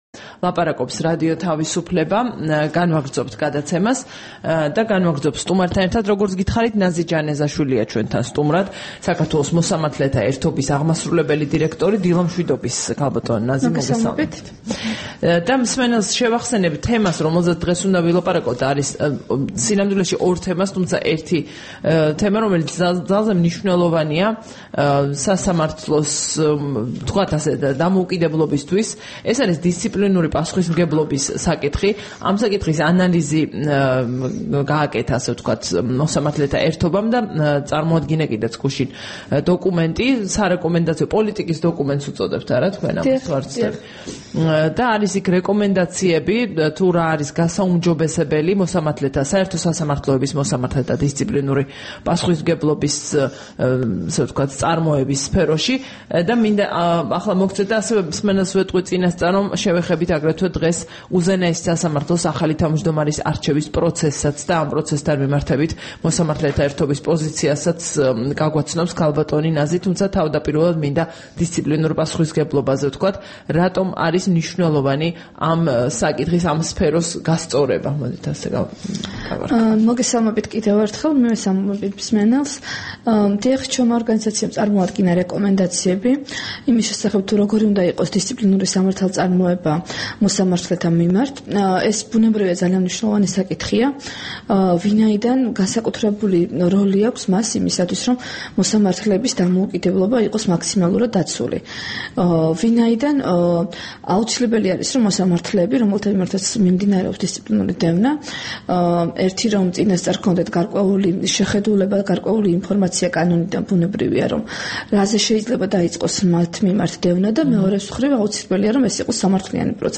სტუმრად ჩვენს ეთერში
საუბარი